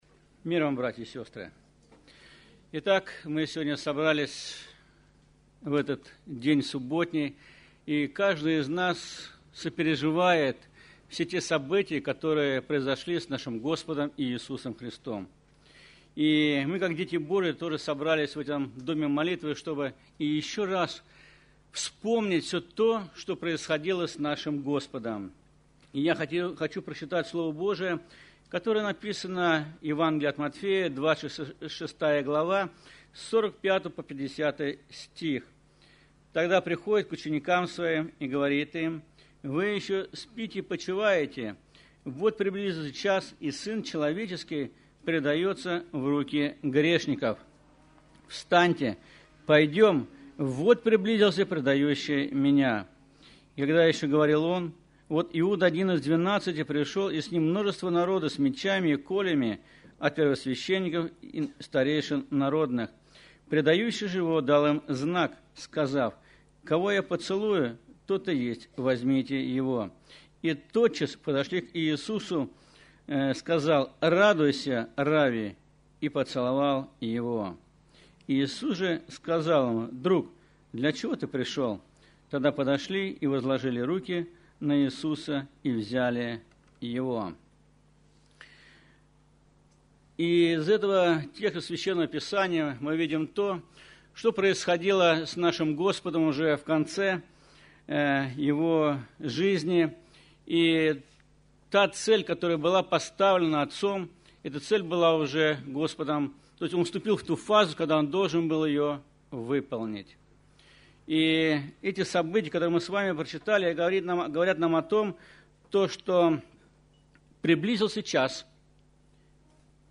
Церковь: Церковь ЕХБ "Голгофа", г. Москва (Местная религиозная организация – Церковь евангельских христиан-баптистов «Голгофа»)